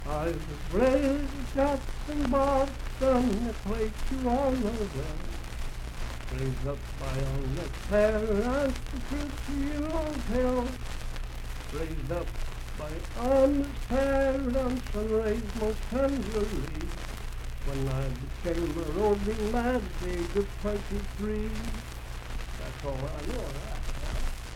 Boston Burglar - West Virginia Folk Music | WVU Libraries
Unaccompanied vocal music
in Mount Storm, W.V.
Verse-refrain 1d(4).
Voice (sung)
Grant County (W. Va.)